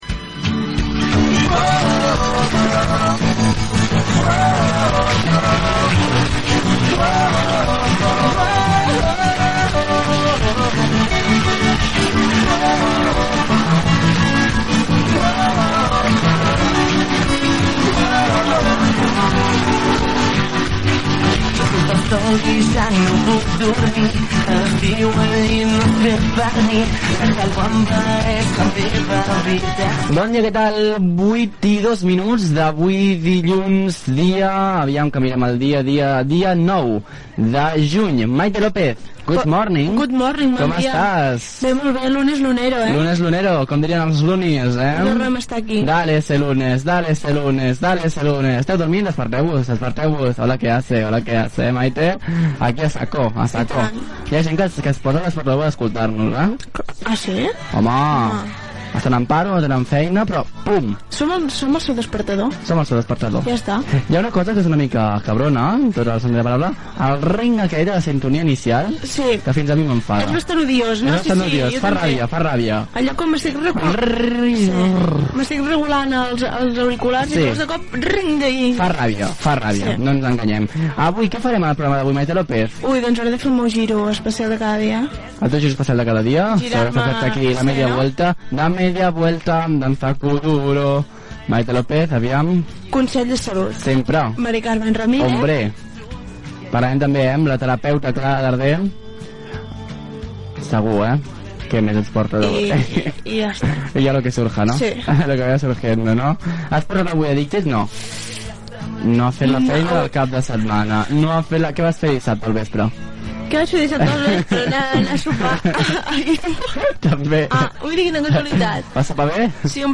Hora, data, presentació, sumari de continguts, comentari sobre el fet al cap de setmana i tema musical.
Entreteniment